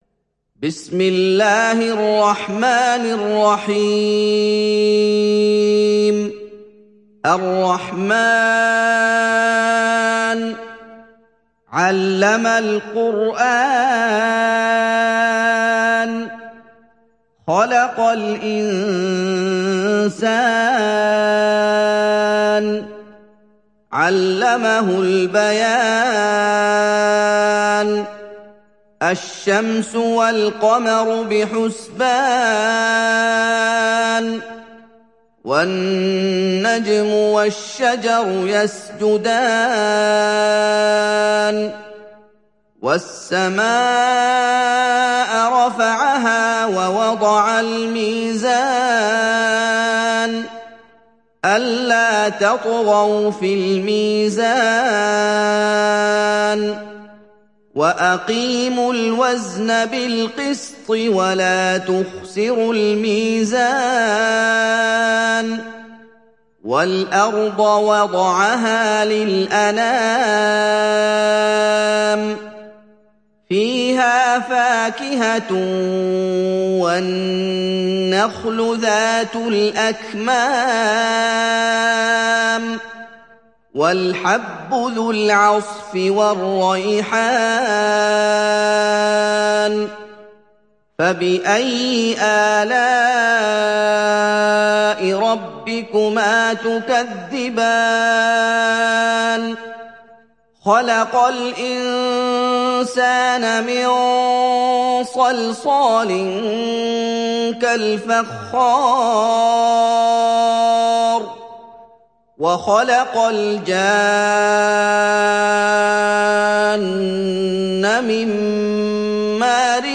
تحميل سورة الرحمن mp3 بصوت محمد أيوب برواية حفص عن عاصم, تحميل استماع القرآن الكريم على الجوال mp3 كاملا بروابط مباشرة وسريعة
تحميل سورة الرحمن محمد أيوب